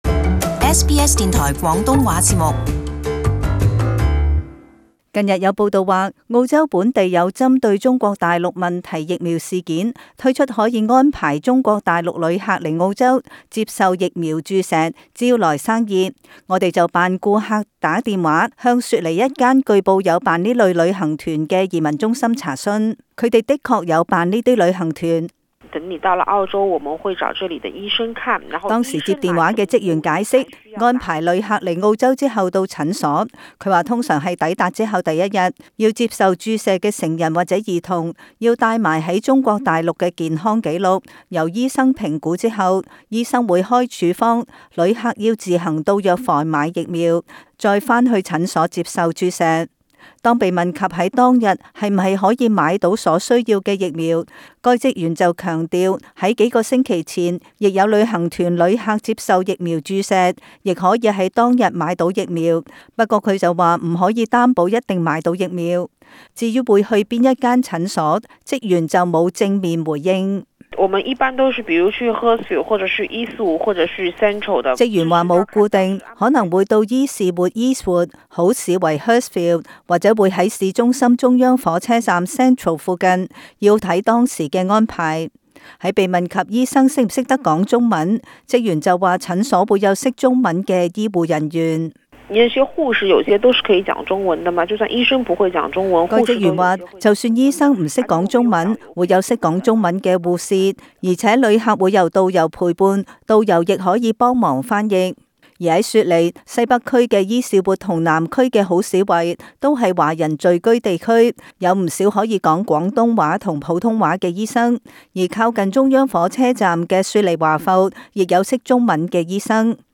【社區專訪】疫苗旅行團(上)